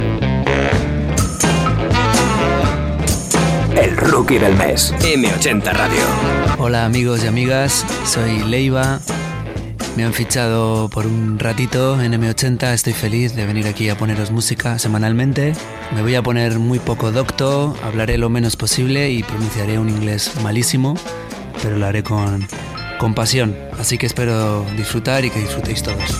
Careta del programa i presentació feta pel canatant Leiva (José Miguel Conejo ) que és qui presentaria aquell mes alguns programes
Musical